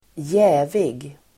Ladda ner uttalet
Uttal: [²j'ä:vig]